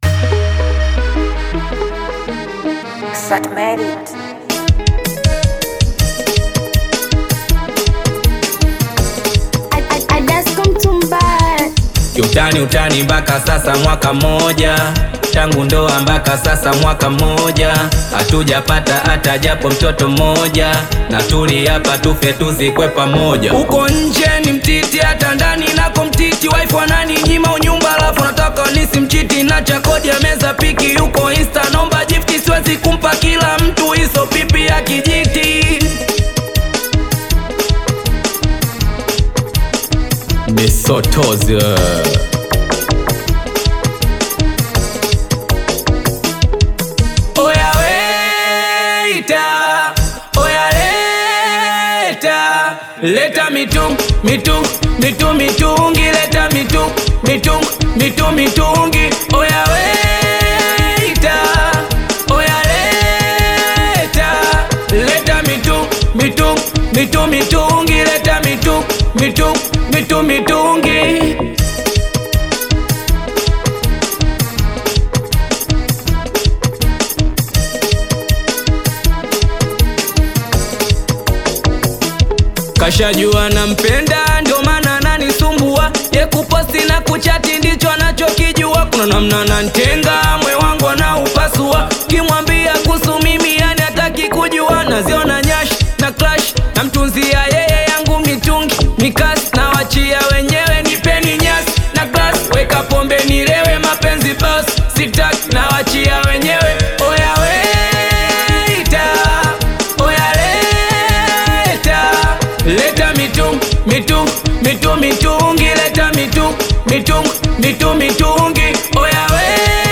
vibrant Tanzanian Singeli track